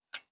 ClockTicking.wav